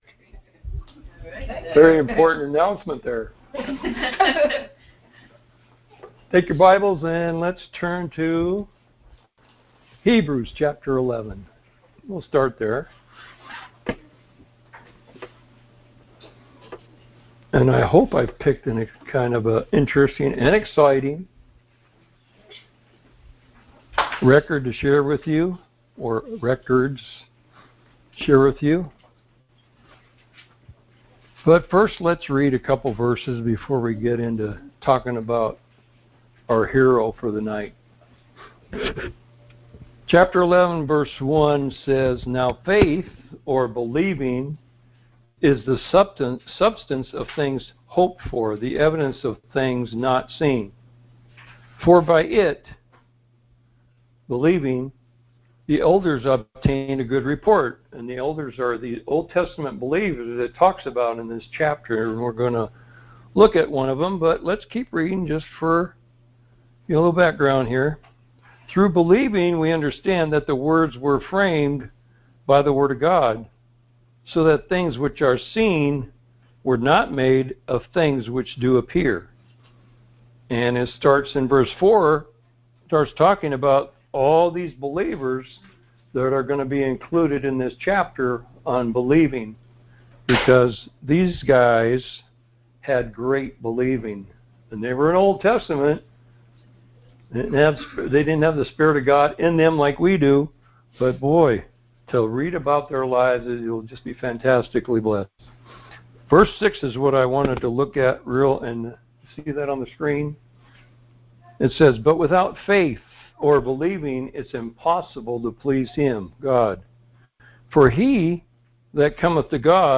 Series: Conference Call Fellowship